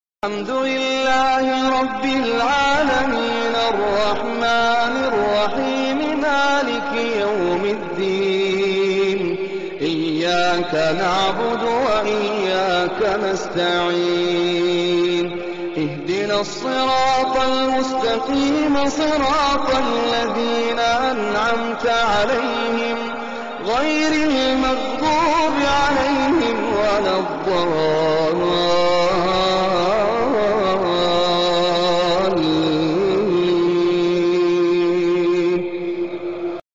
Alquran rekaman tahun 1940 suara emas